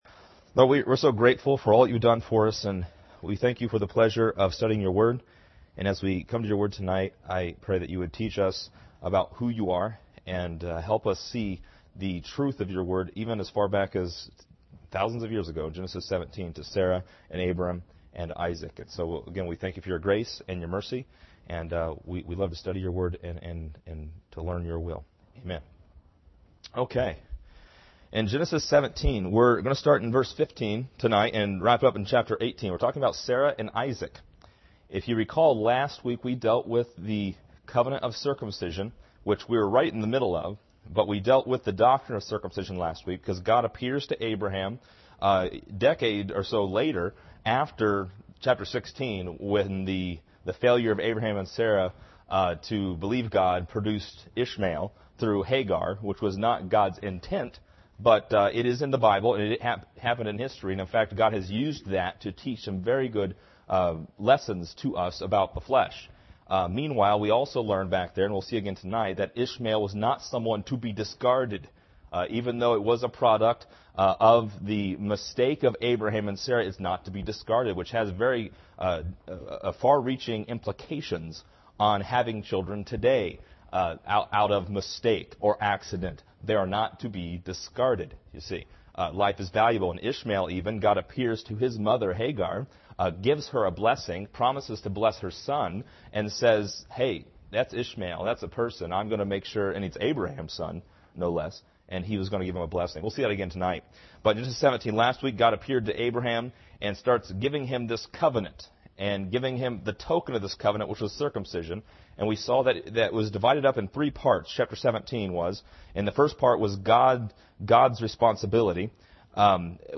This lesson is part 28 in a verse by verse study through Genesis titled: Sarah and Isaac.